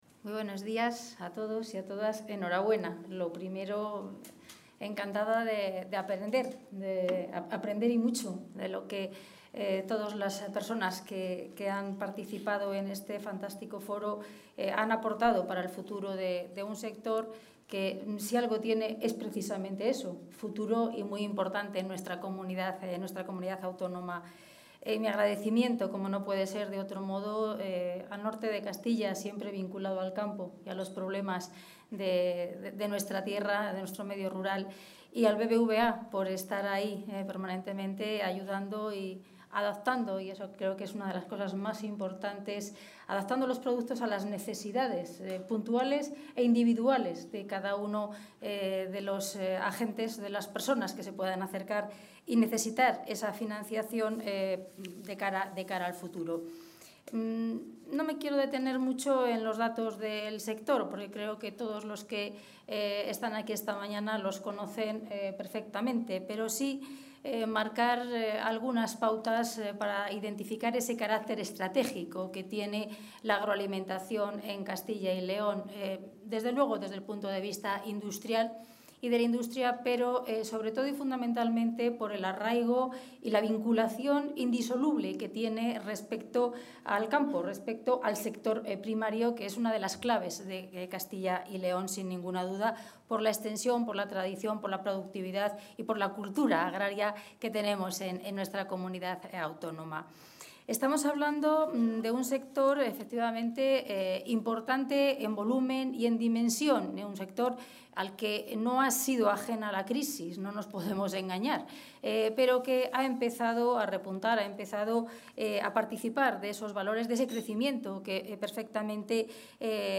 Material audiovisual del desayuno informativo 'Los grandes retos de la industria agroalimentaria en Castilla y León'
Milagros Marcos clausura el desayuno informativo 'Los grandes retos de la industria agroalimentaria en Castilla y León' organizado por El Norte de Castilla y BBVA.